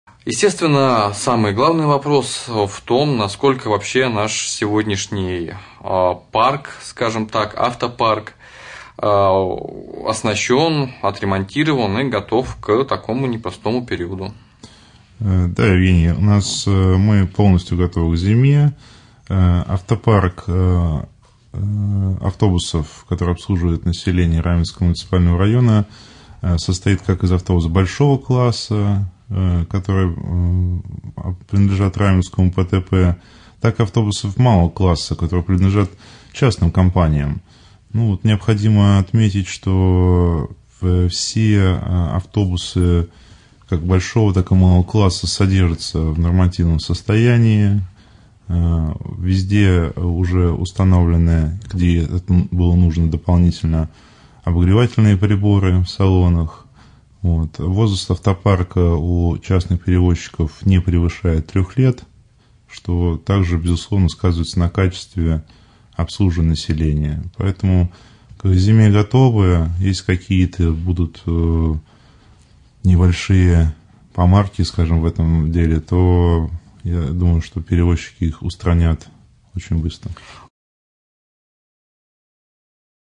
Автотранспорт Раменского пассажирского автотранспортного предприятия полностью подготовлен в зимнему периоду, об этом в ходе прямого эфира на Раменском радио 8 ноября рассказал начальник Управления инвестиций, транспорта и связи администрации Раменского района Андрей Скибо.